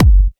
• HQ Electronic Bass Drum Sample G Key 574.wav
Royality free steel kick drum sound tuned to the G note. Loudest frequency: 382Hz
hq-electronic-bass-drum-sample-g-key-574-sQE.wav